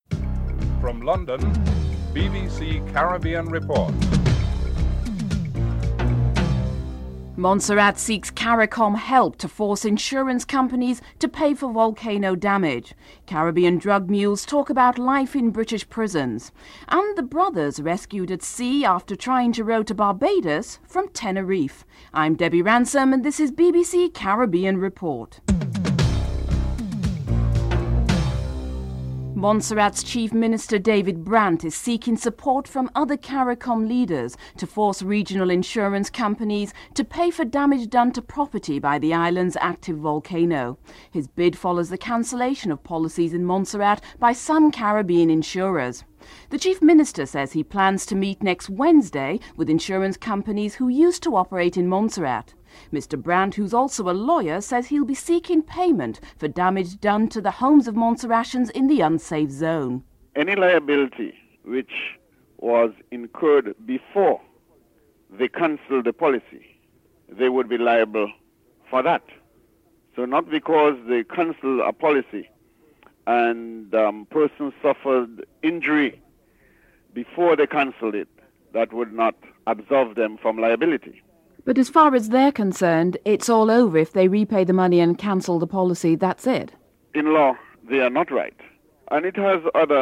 Some drug mules were interviewed